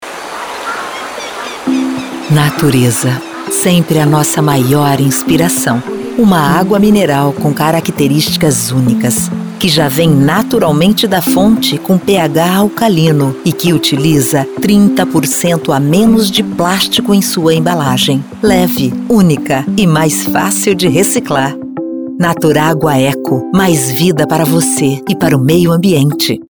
Voz motivacional /Naturagua
Voz Padrão - Grave 00:26
Owns an at home recording studio.